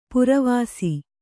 ♪ puravāsi